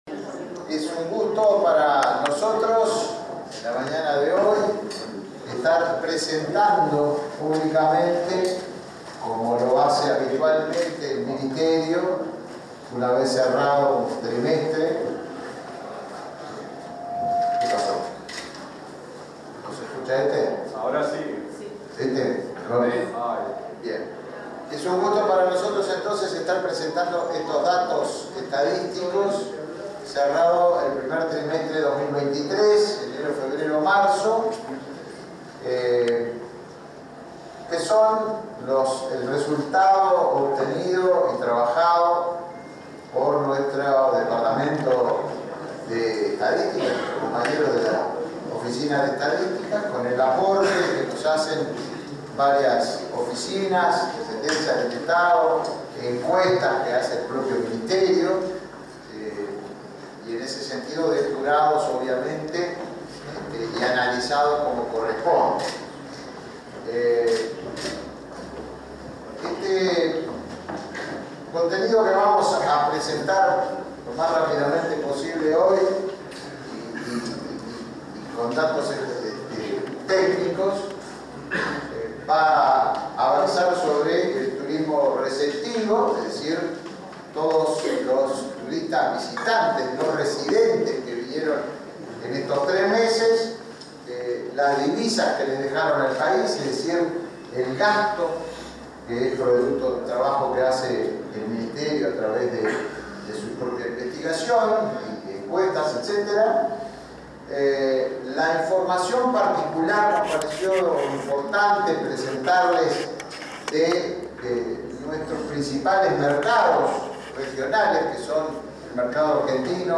Palabras del ministro de Turismo, Tabaré Viera | Presidencia Uruguay
El ministro de Turismo, Tabaré Viera, presentó este miércoles 19, en la sede de la cartera estatal, el balance del área que le compete respecto al